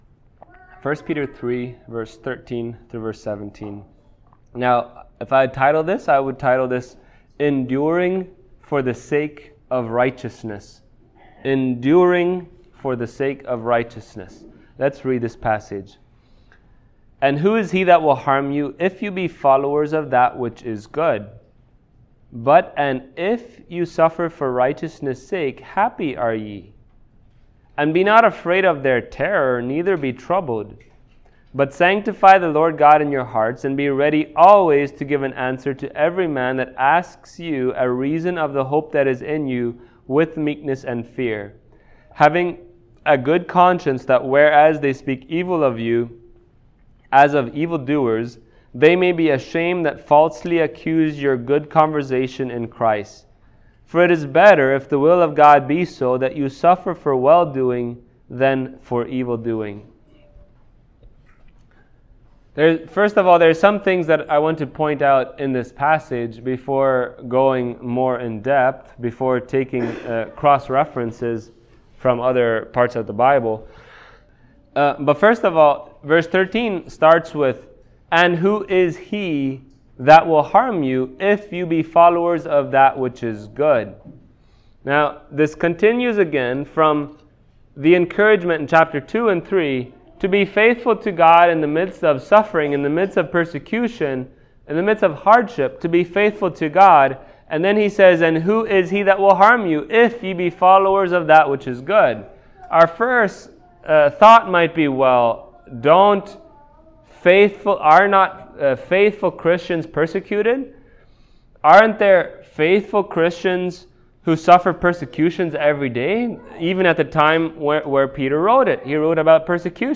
Passage: 1 Peter 3:18-22 Service Type: Sunday Morning